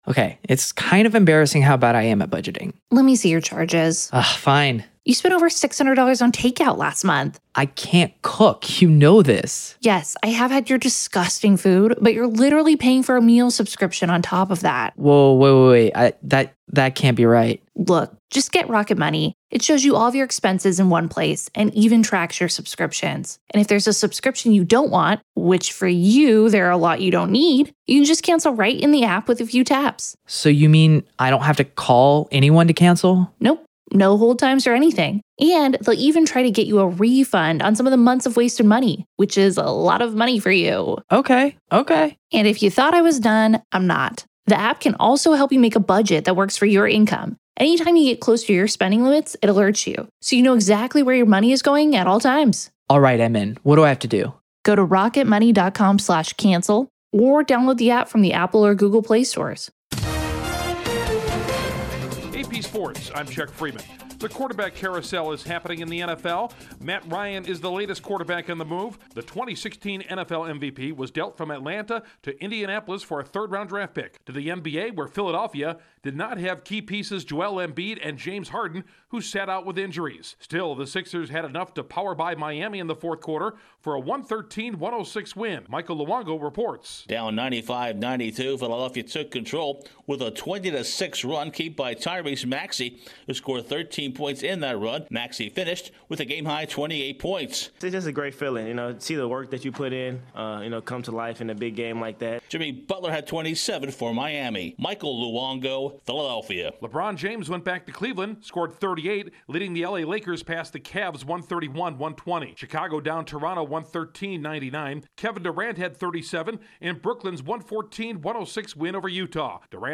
The Colts trade for a fomer MVP, a fourth quarter run leads the 76ers over the Heat, and the Bruins edge the Canadiens in overtime. Correspondent